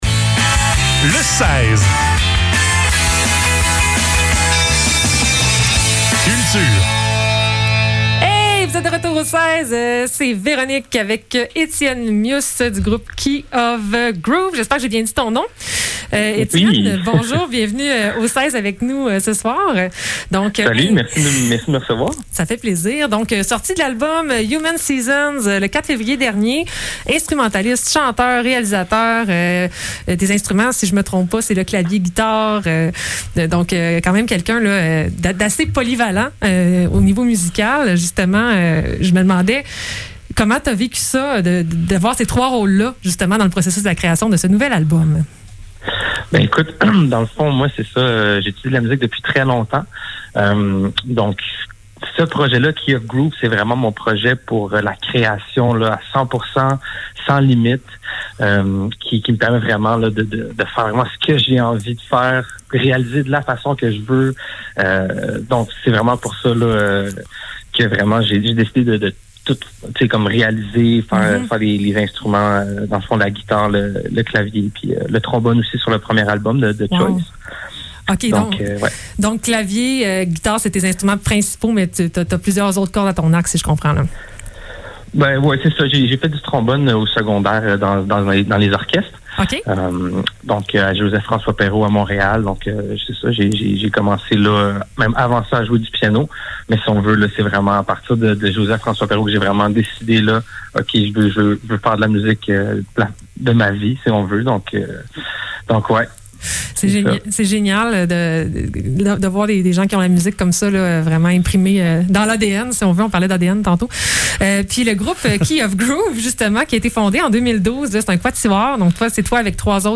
Les entrevues de CFAK Le seize - Entrevue